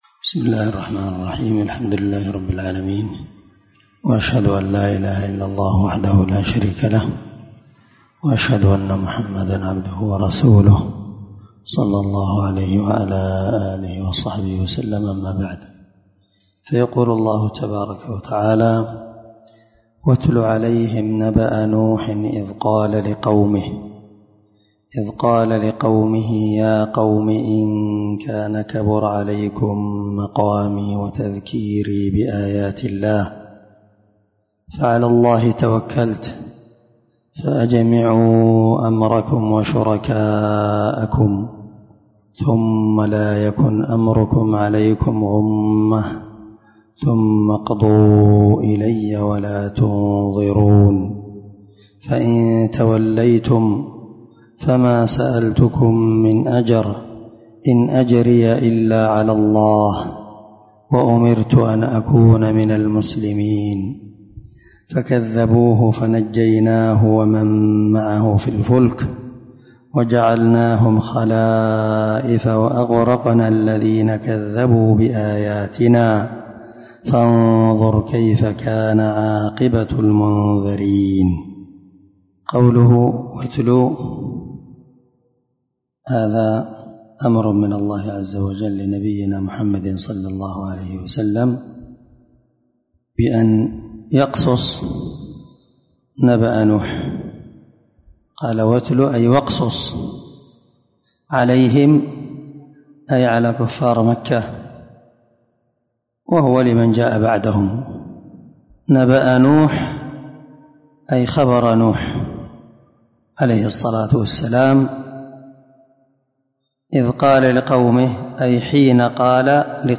610الدرس 26 تفسير آية ( 71- 73) من سورة يونس من تفسير القران الكريم مع قراءة لتفسير السعدي